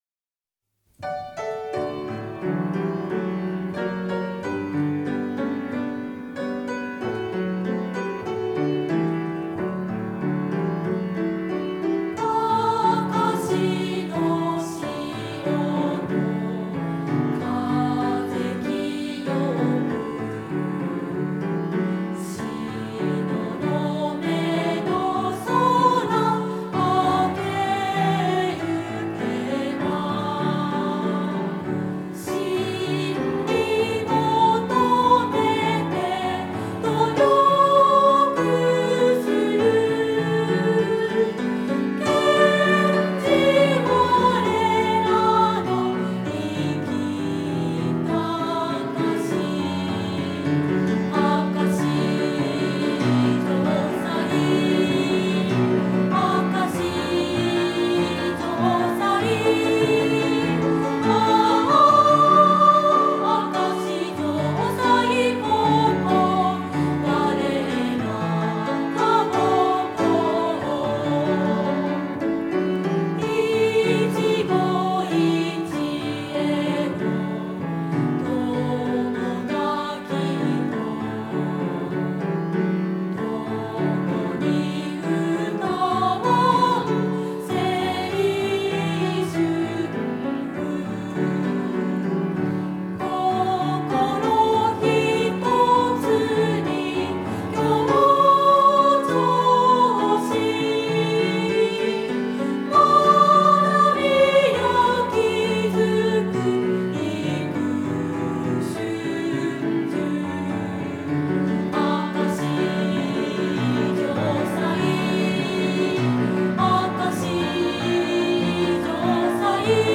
■コーラス■　♪
chorus.wma